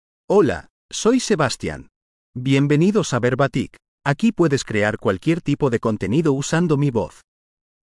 Sebastian — Male Spanish (Spain) AI Voice | TTS, Voice Cloning & Video | Verbatik AI
Sebastian is a male AI voice for Spanish (Spain).
Voice sample
Listen to Sebastian's male Spanish voice.
Sebastian delivers clear pronunciation with authentic Spain Spanish intonation, making your content sound professionally produced.